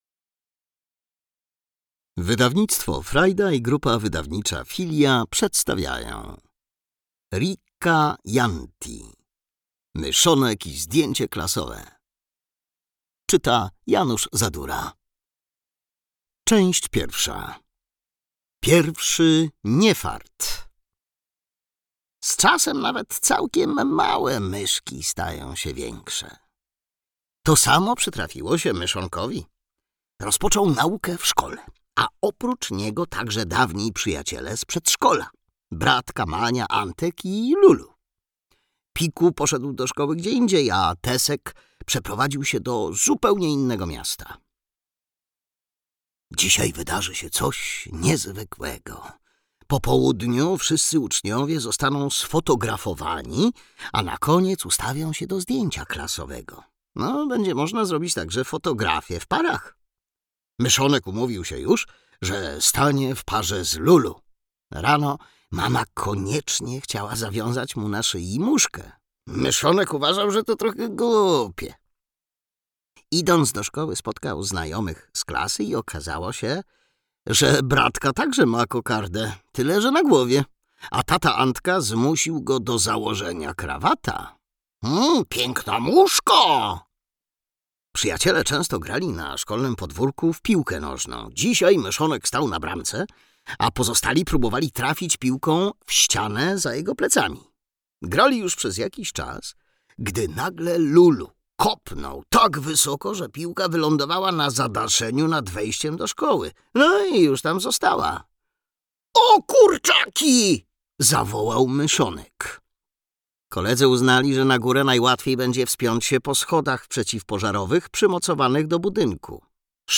Myszonek i zdjęcie klasowe - Riikka Jäntti - audiobook + książka